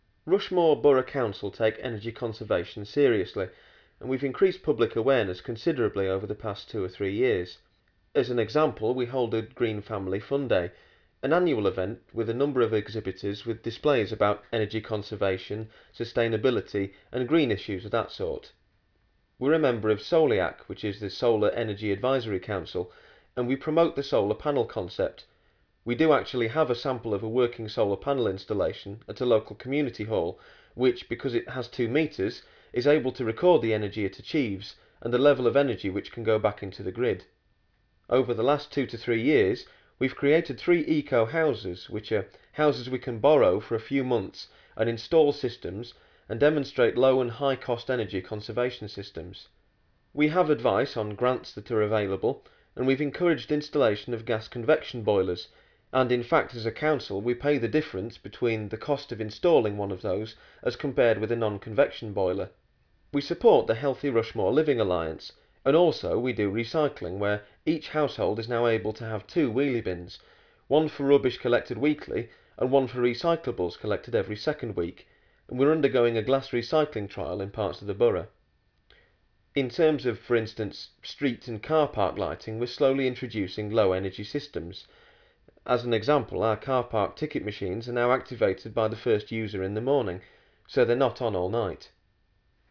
listen to this interview with a councillor from Rushmoor Borough or read this transcript.